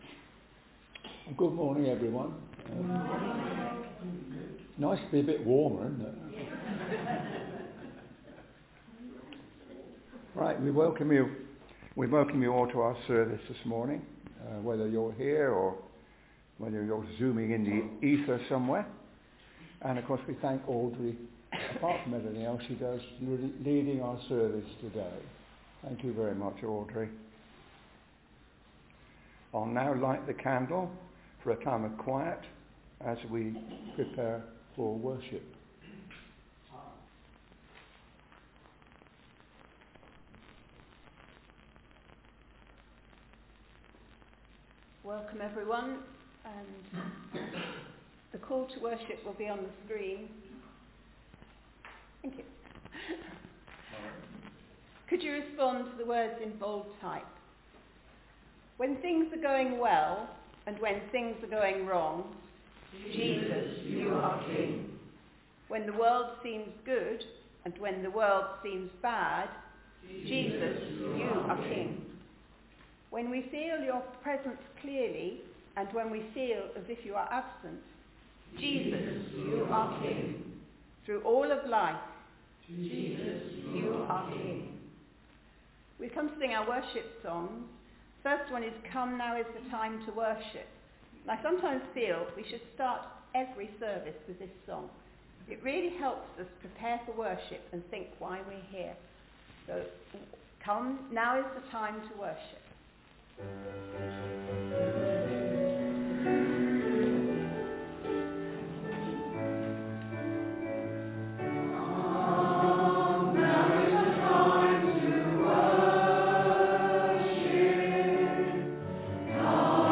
All music and hymns used by permission CCLI 4409 and 136088 and One License 734127-A.